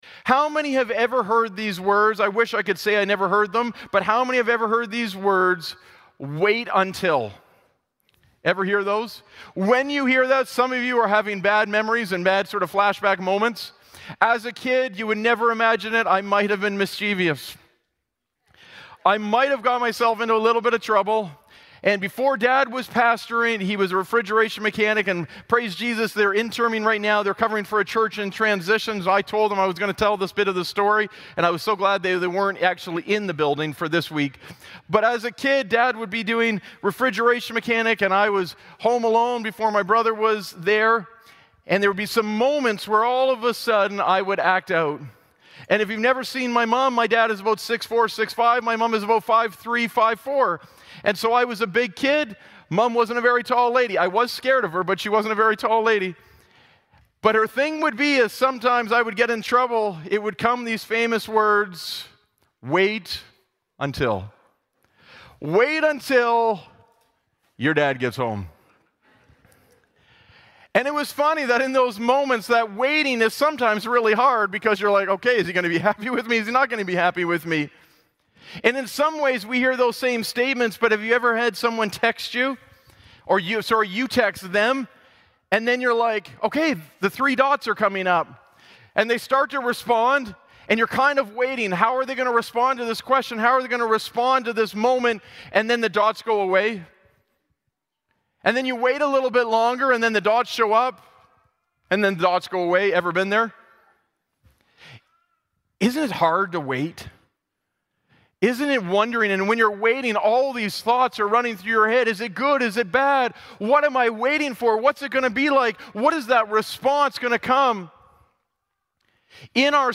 Glad Tidings Church (Sudbury) - Sermon Podcast Wait Until Play Episode Pause Episode Mute/Unmute Episode Rewind 10 Seconds 1x Fast Forward 30 seconds 00:00 / 00:43:45 Subscribe Share RSS Feed Share Link Embed